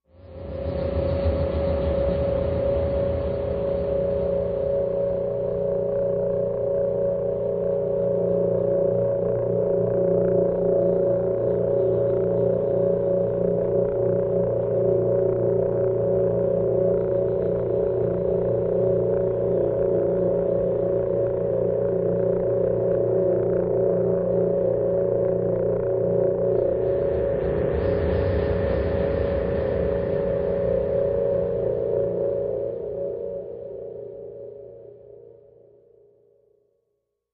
Pulsing
Brainfreeze Steady Atonal Pulse Cold